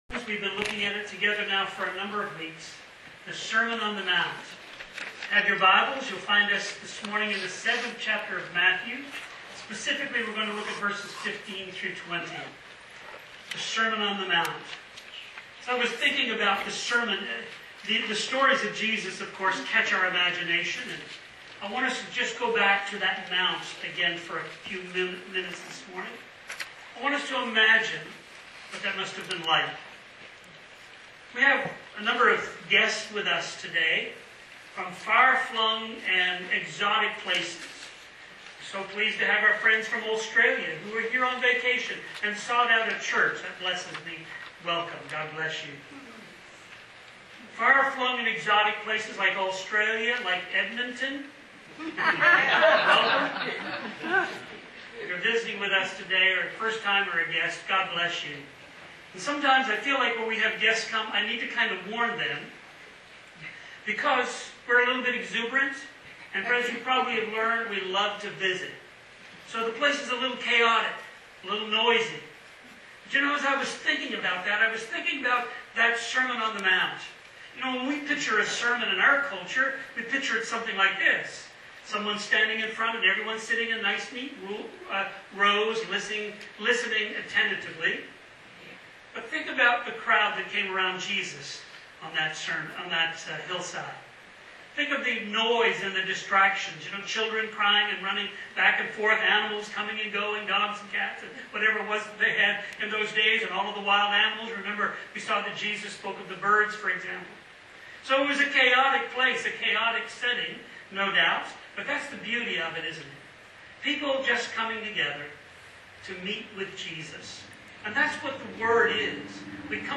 A Warning Matthew 7:15-20 Sermon on the Mount Series | Burnaby North Baptist Church